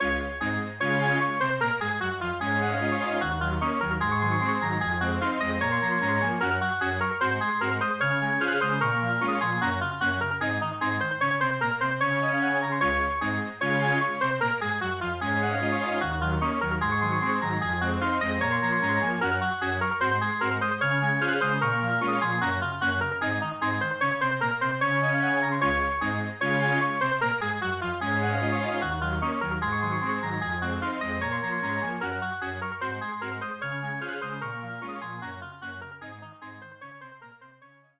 Application of EBU R 128 to all BGM